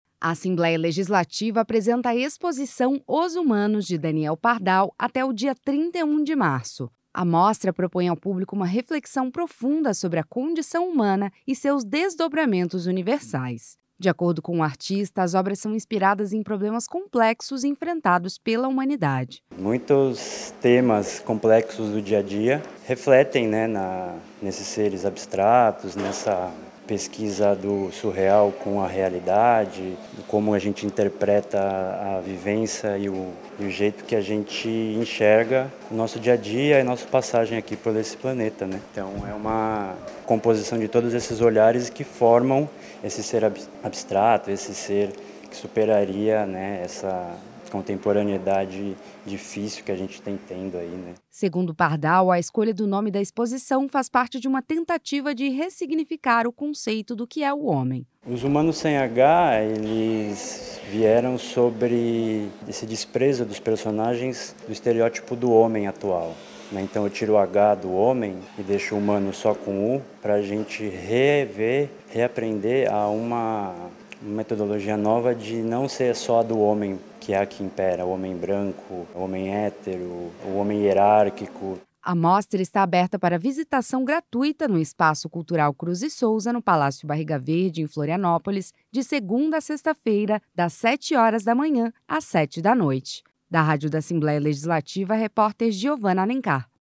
Entrevista com:
Repórter